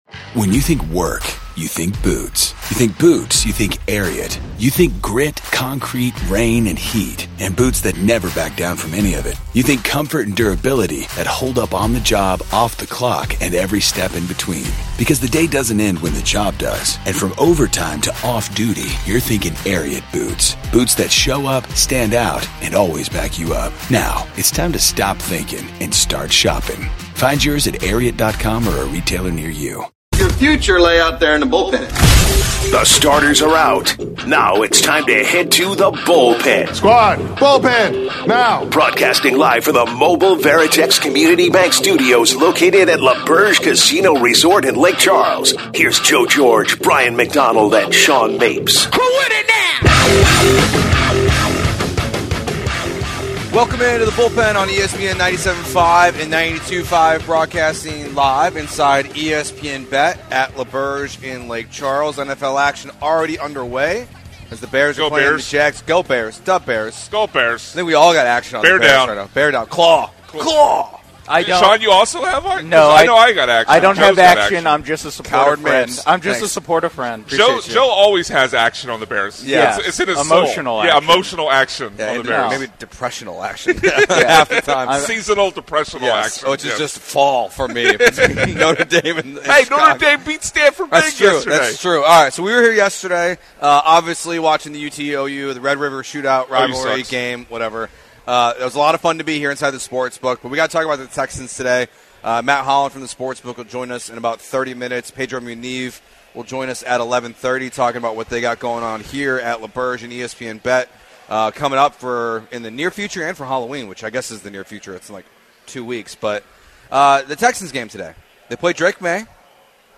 10/13/2024 The Bullpen live from L'Auberge Casino Resort Hour 1